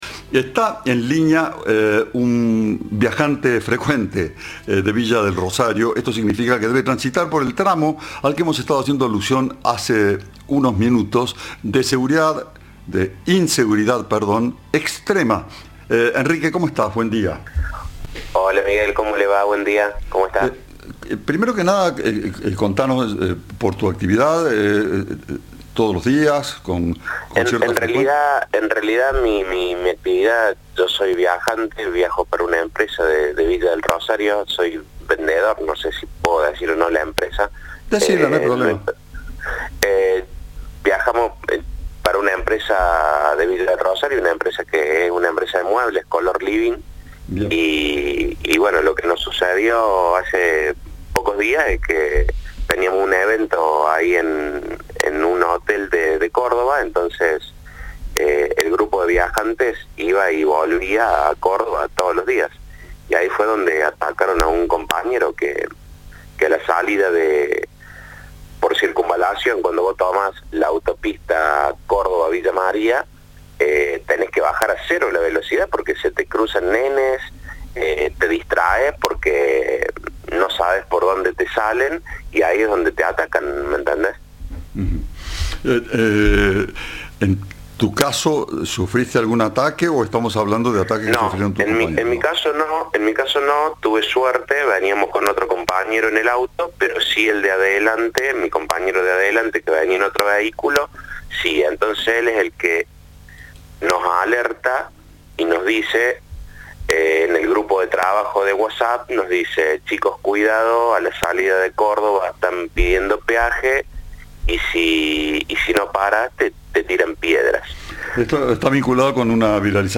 En diálogo con Cadena 3, el conductor afirmó que el proyectil pegó en un parante.